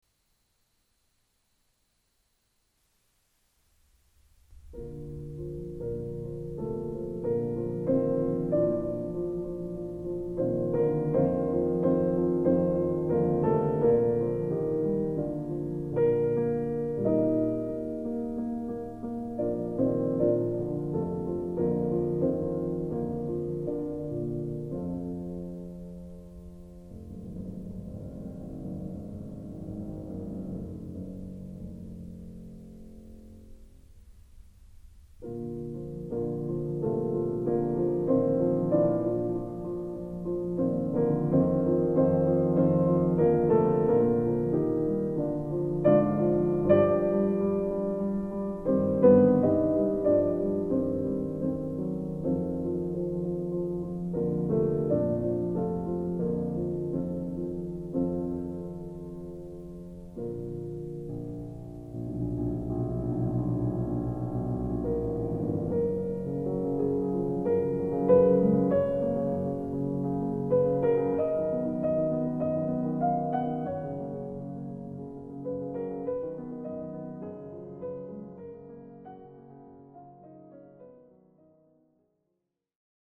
Everything stops.